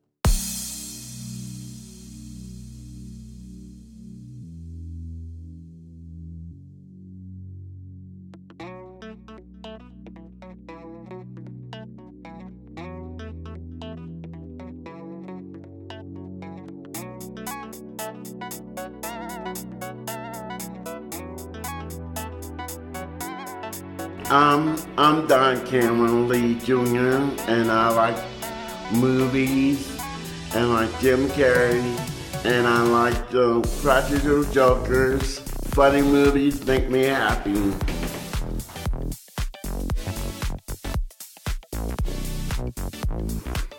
(captured from the vimeo livestream)